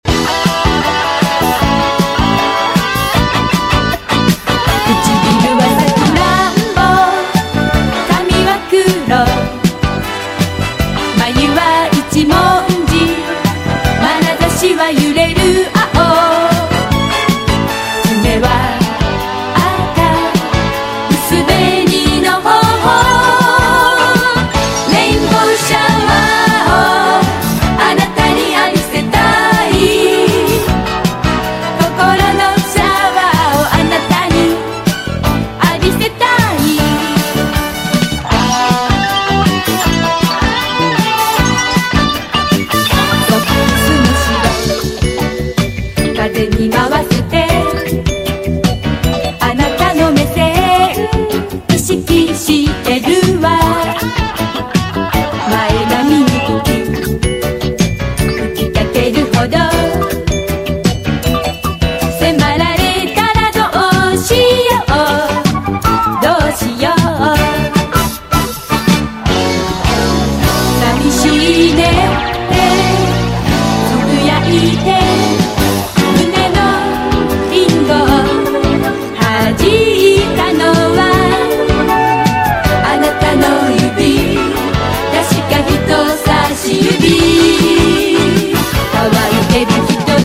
OLDIES / ROCK ＆ ROLL
日本語オールディーズ・メガミックス状態のパーティー・ナンバー！